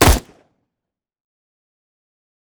Assault Rifle Shot 1.wav